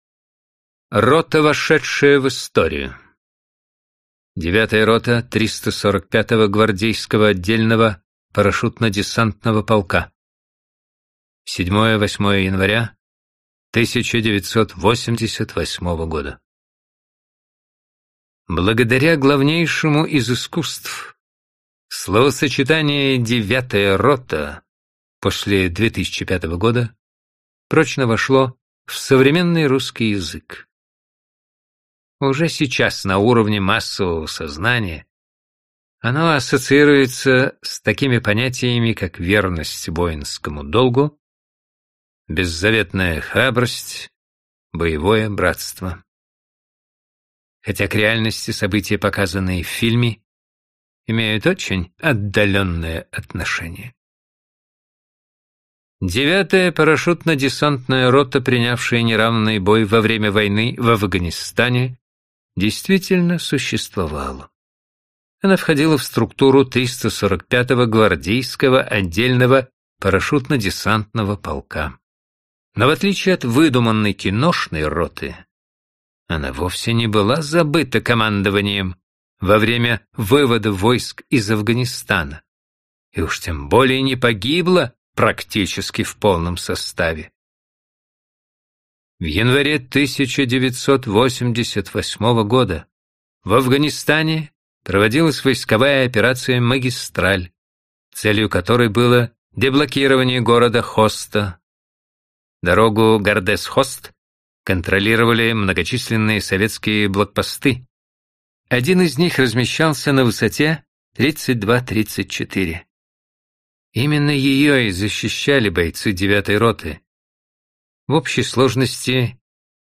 Аудиокнига Герои России | Библиотека аудиокниг